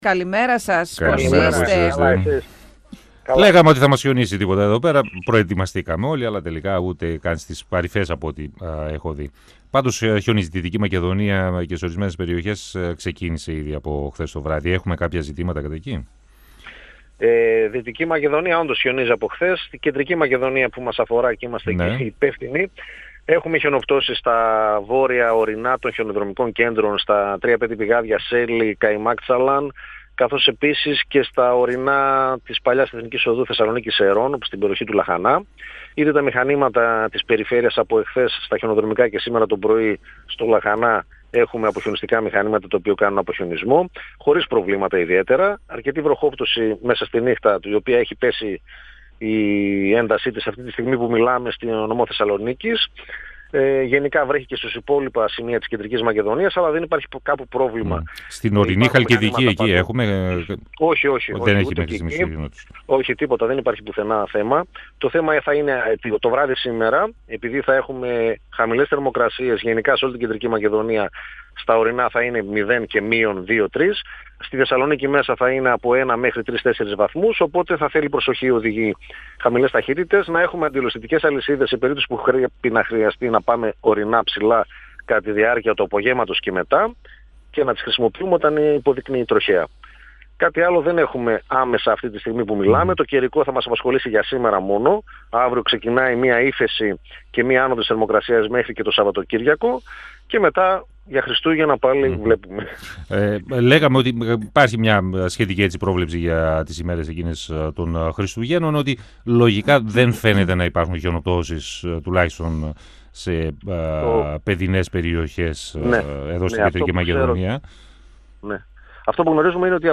μιλώντας στον 102FM της ΕΡΤ3 και πρόσθεσε ότι σύμφωνα με τους μετεωρολόγους